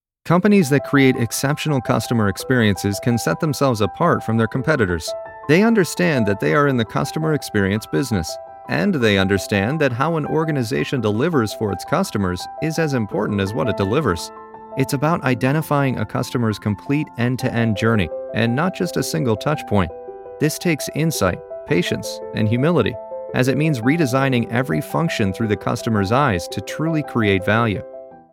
Male
My voice has been described as honest, warm, soothing, articulate, relatable, sincere, natural, conversational, friendly, powerful, intelligent and "the guy next door."
Narration